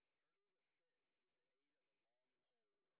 sp09_train_snr10.wav